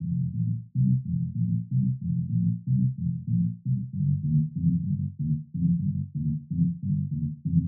basse1.wav